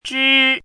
chinese-voice - 汉字语音库
zhi1.mp3